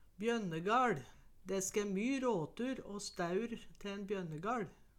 bjønnegaL - Numedalsmål (en-US)